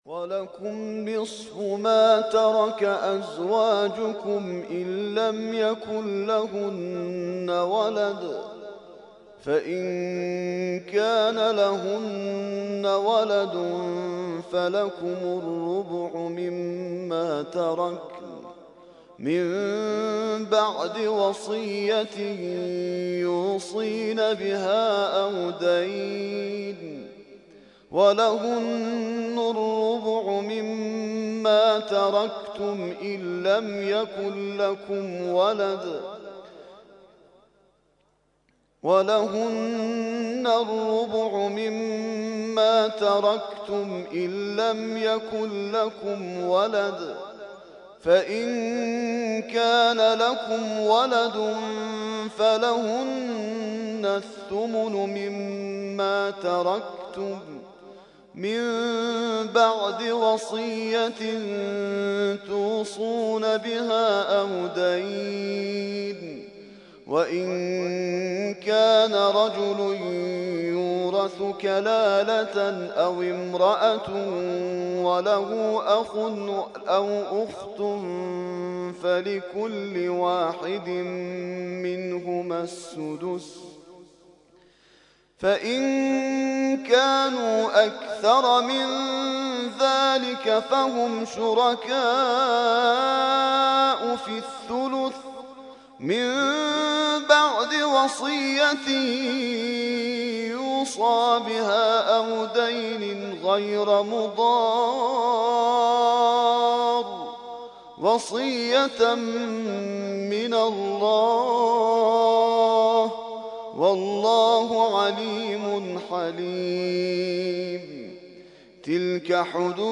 ترتیل خوانی جزء ۴ قرآن کریم در سال ۱۳۹۵